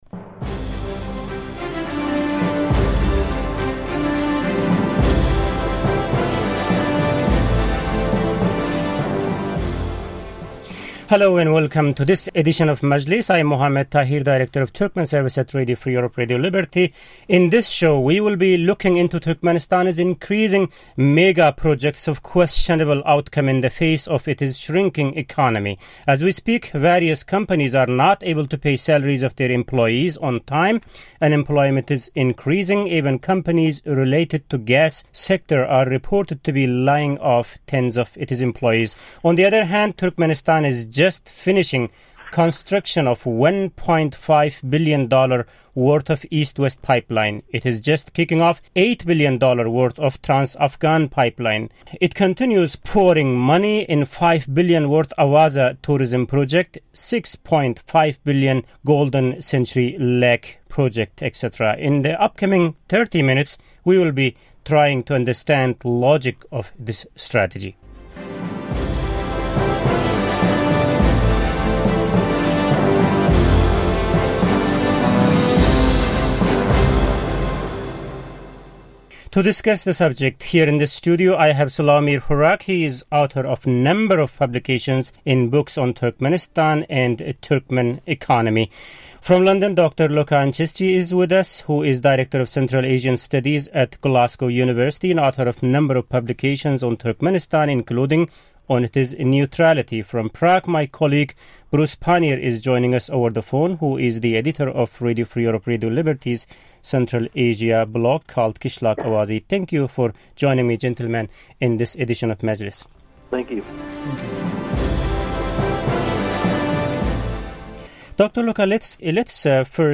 RFE/RL Turkmen Service roundtable - Economy (Nov 2015)